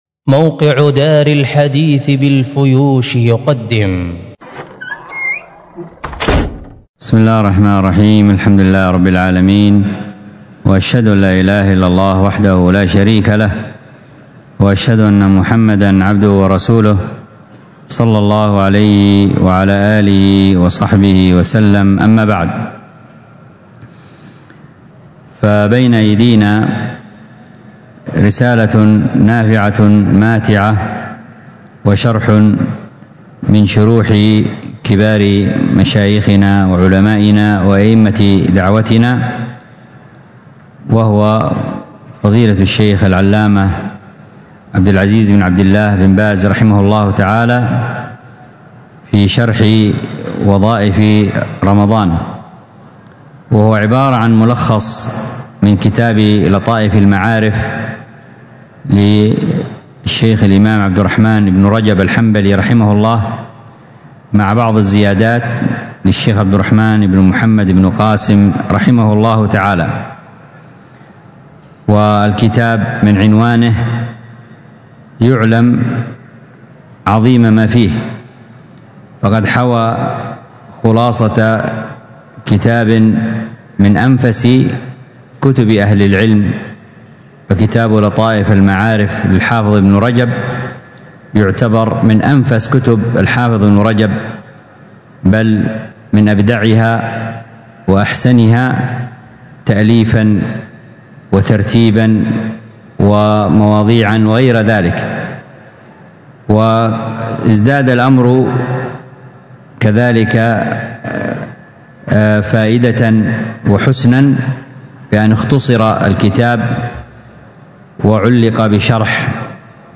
الدرس في شرح أصول في التفسير للعثيمين 49، الدرس التاسع والأربعون:من:( تكرار القصص ... 5ـ ظهور صدق القران وأنه من عند الله تعالى حيث تأتي هذه القصص متنوعة بدون تناقض ).